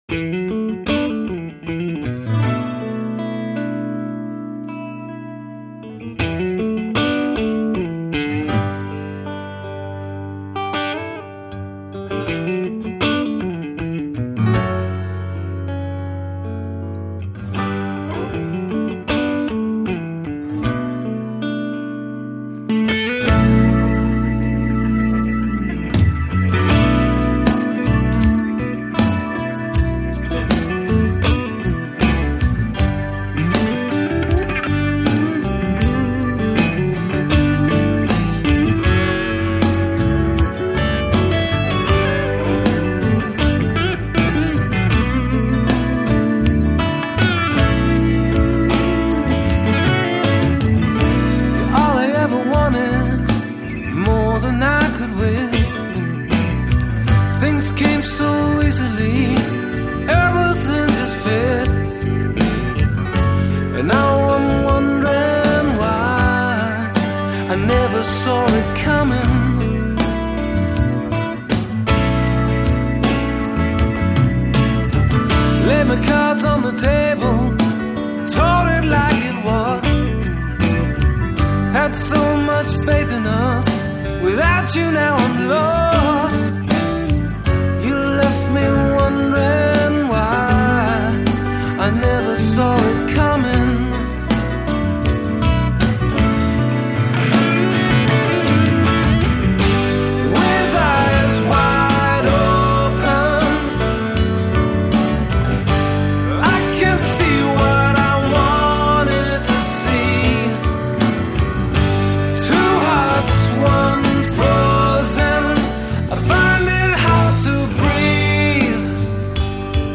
Жанр: Blues